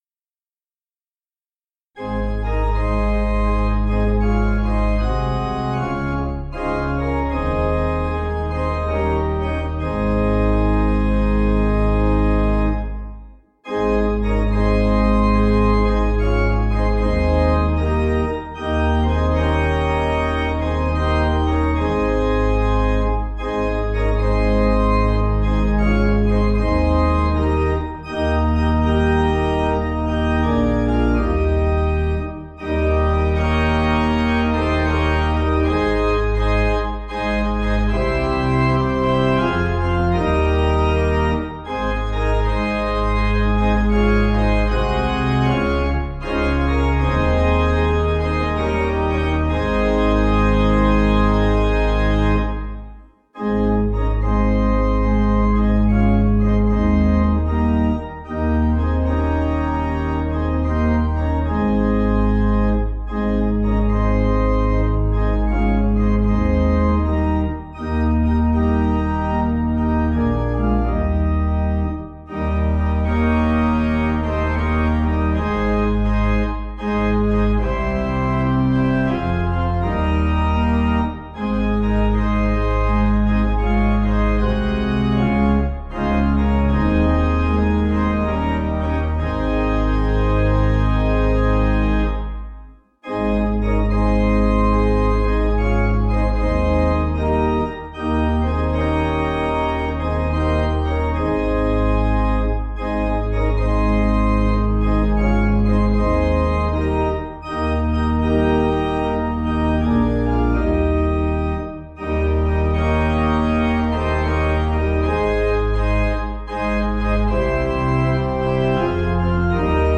(CM)   4/Ab